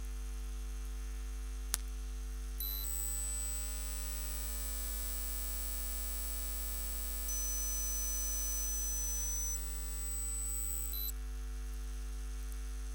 Questi disturbi si presentano come "burst" intermittenti che durano da qualche secondo a qualche minuto; si suddividono inoltre in due sotto-famiglie corrispondenti a due diverse origini.
Anche questi disturbi si osservano assieme, si susseguono mescolandosi e intercalandosi l'uno con l'altro.
Può succedere che alcune armoniche si allarghino talmente tanto da diventare un disturbo quasi bianco in una banda di decine di kHz, da 12 kHz in poi. L'origine di questa seconda classe di disturbi sembra essere un'altra gru, data la coincidenza dei disturbi con i suoi movimenti.
disturbo_cantiere2.wav